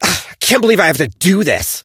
edgar_start_vo_02.ogg